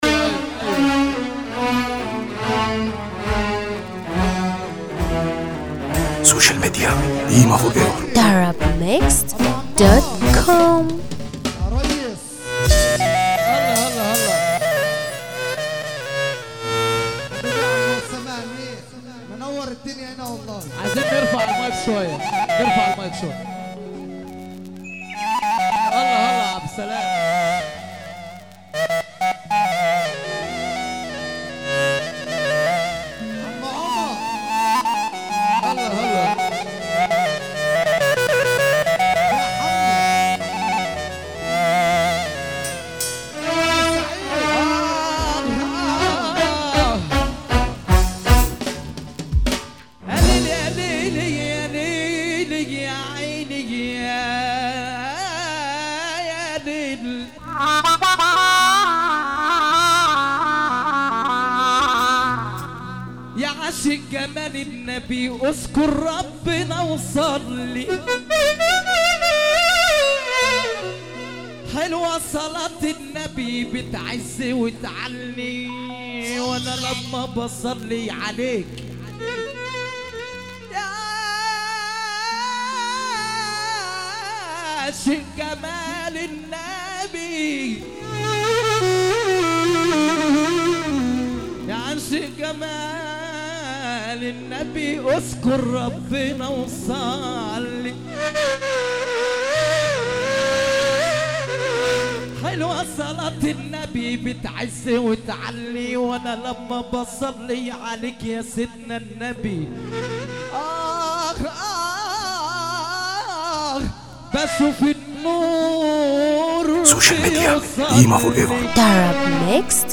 موال